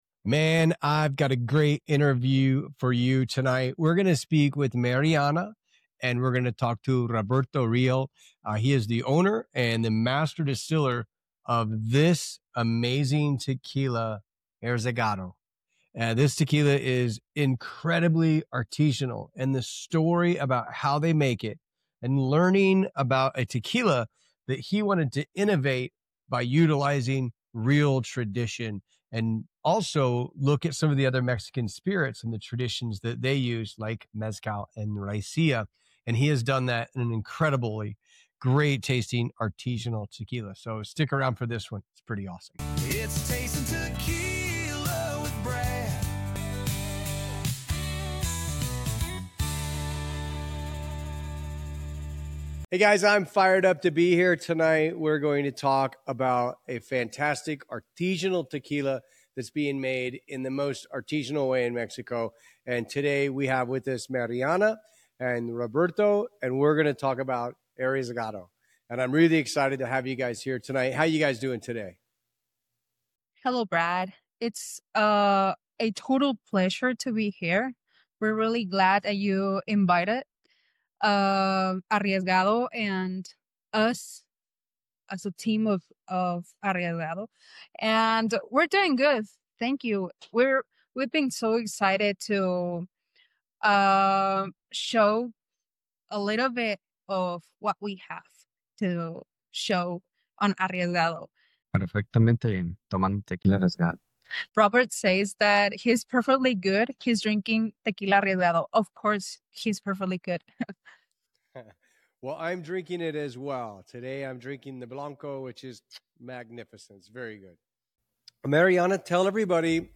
The Ancestral Secrets of Arriesgado Tequila | Interview